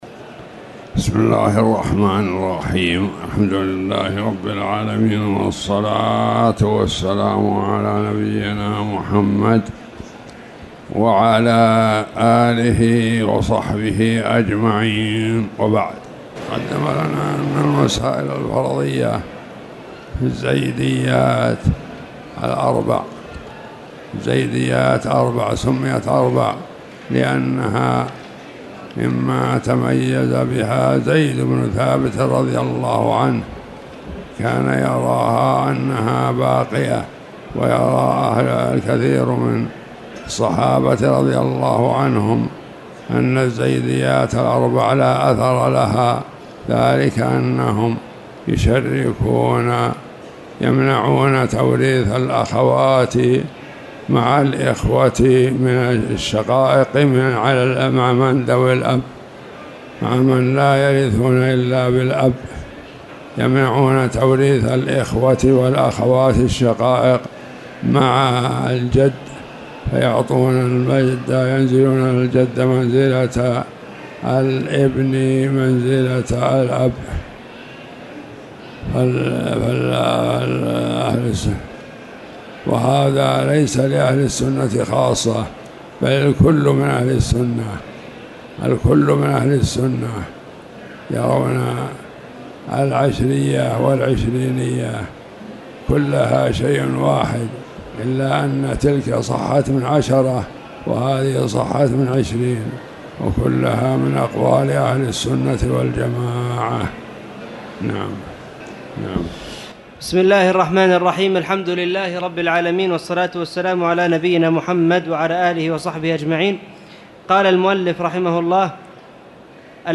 تاريخ النشر ٦ شعبان ١٤٣٨ هـ المكان: المسجد الحرام الشيخ